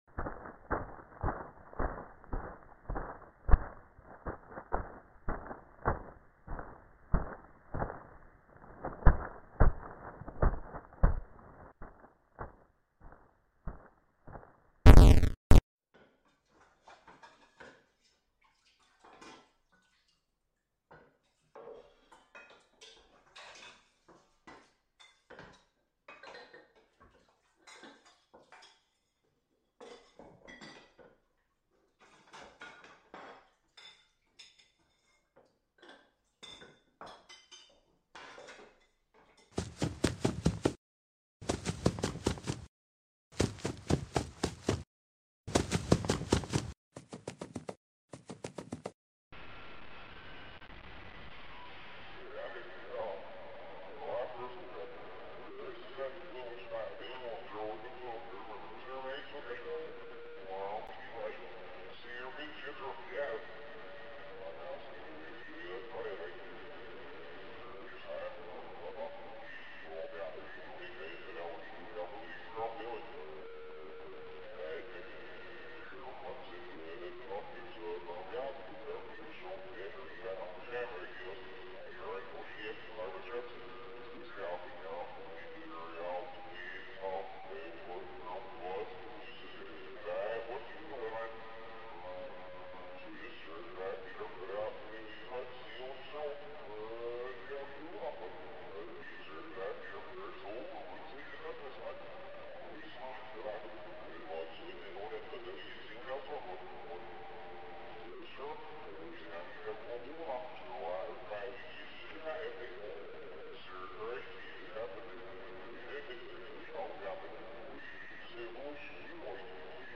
Five Nights at Freddy's 4 sound effects free download
Five Nights at Freddy's 4 in-game sounds (3/?)